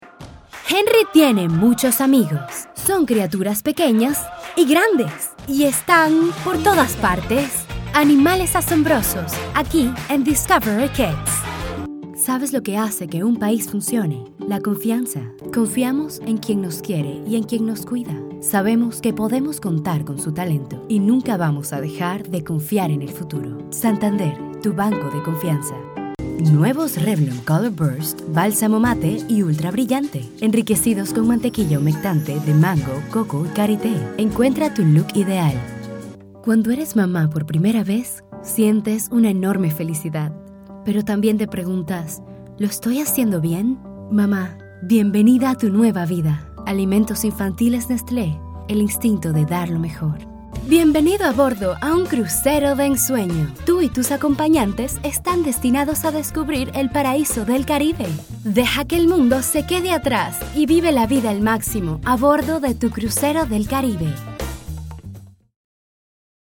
My voice has a warm and smooth natural tone. It has been described as luxurious, millennial, sensual, hip, sophisticated.
Profesional Singer and voice over talent